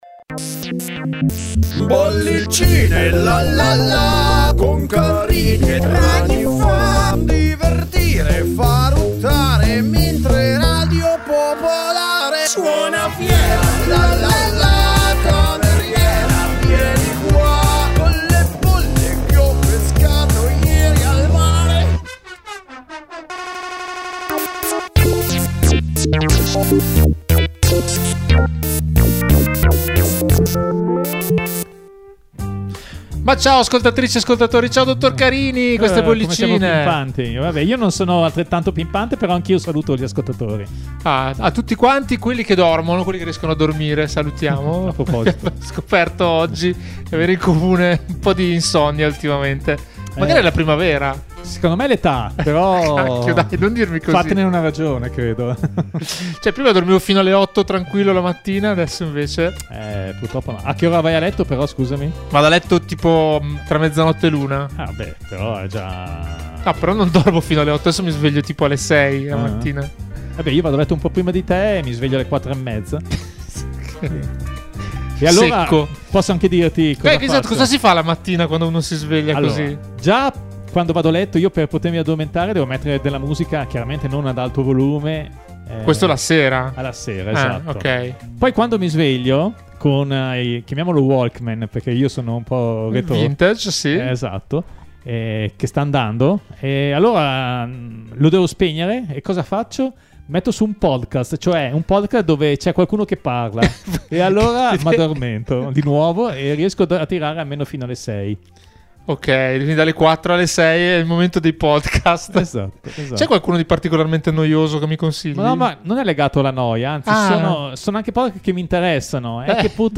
Che cos’hanno in comune gli Area e i cartoni giapponesi? Quali sono i vinili più rari al mondo? Giunta alla stagione numero 17, Bollicine - ogni domenica, dalle 16.30 alle 17.30 - racconta la musica attraverso le sue storie e le voci dei suoi protagonisti: in ogni puntata un filo rosso a cui sono legate una decina di canzoni, con un occhio di riguardo per la musica italiana.